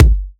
Index of /99Sounds Music Loops/Drum Oneshots/Twilight - Dance Drum Kit/Kicks